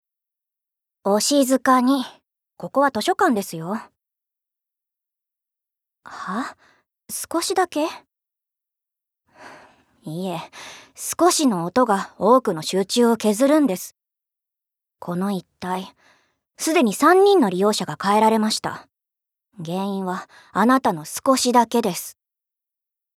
ボイスサンプル
セリフ５